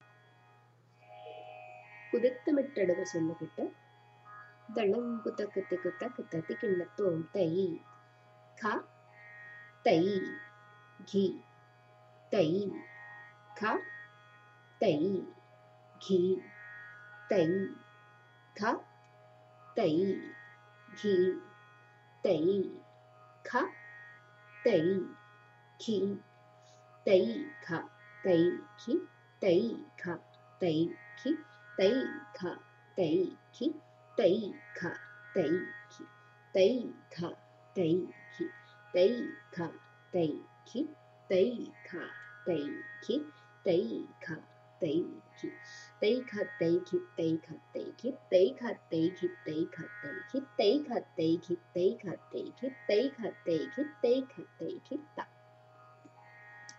Here is the sollukettu for KudittaMetta Adavu. The Bols are Tai Gha, Tai Ghi.